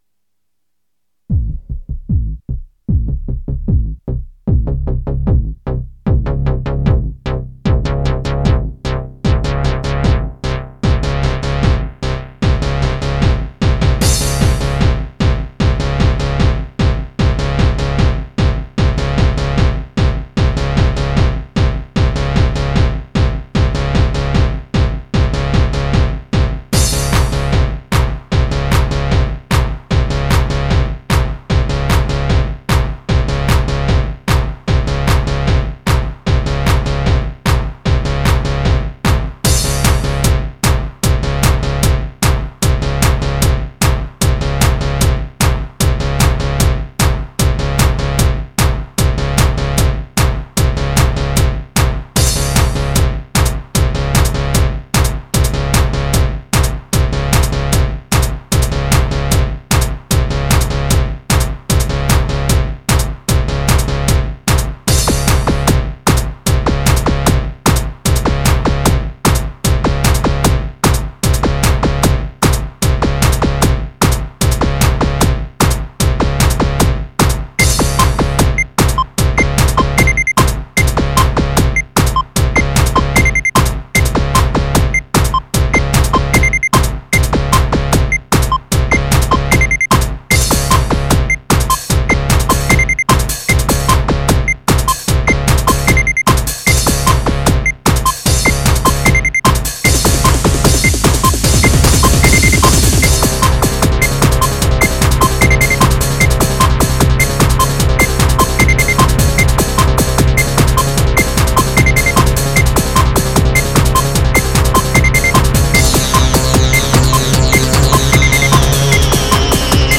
Genre：Techno